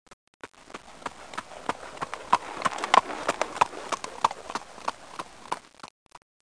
סוס_דוהר.mp3